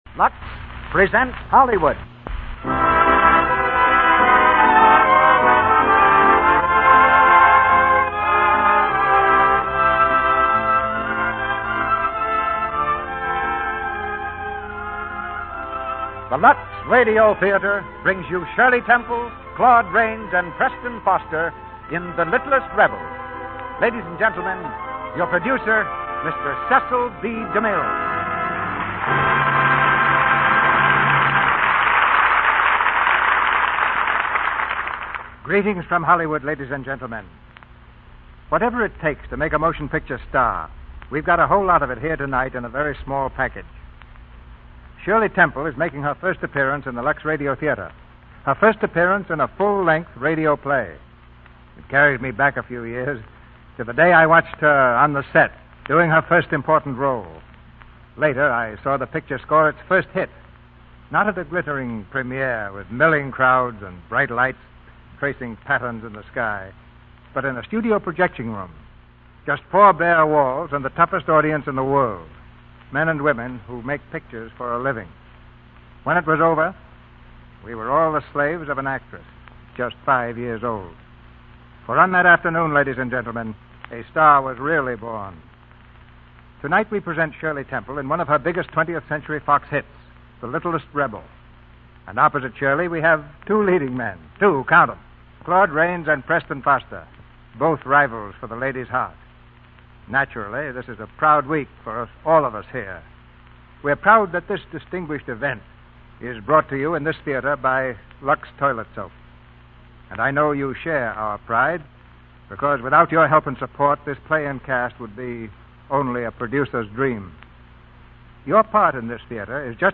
The Littlest Rebel, starring Shirley Temple, Claude Rains, Preston Foster